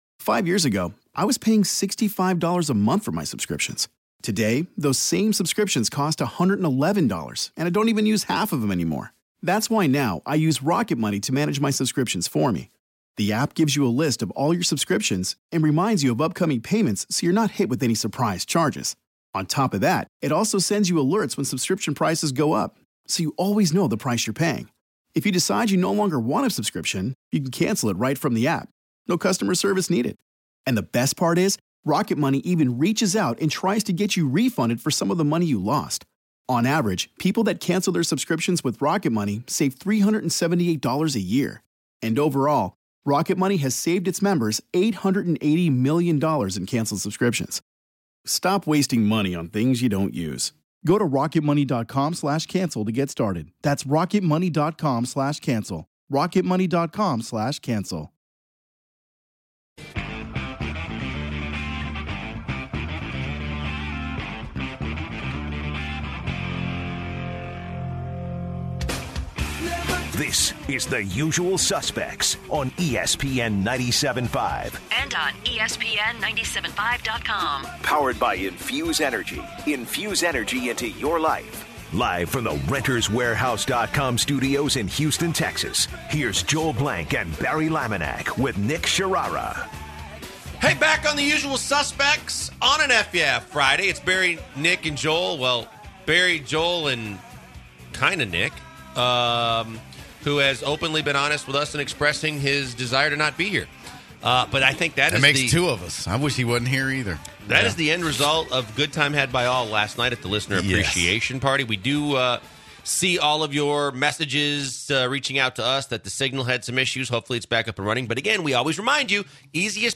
In the second hour, the Usual Suspects continue take calls and recall the happenings during the ESPN 97.5 Listener Appreciation Party. Many callers expressed that they really enjoyed their time at the listener party.